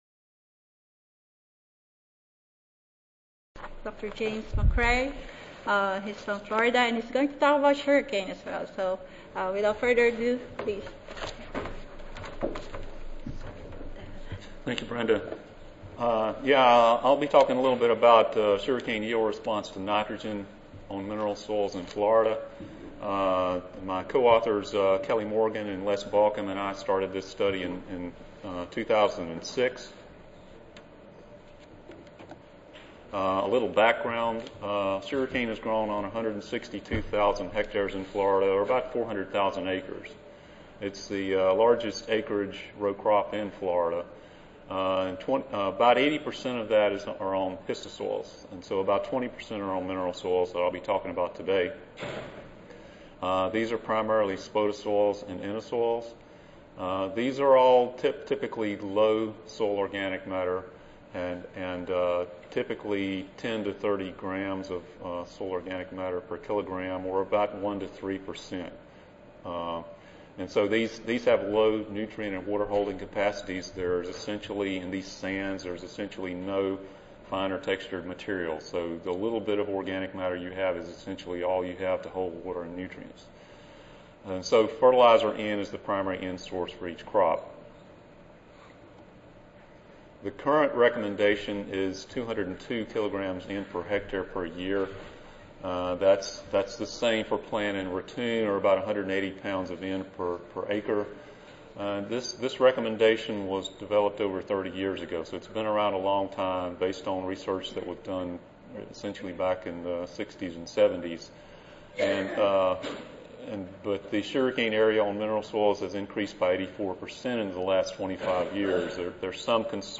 University of Florida Recorded Presentation Audio File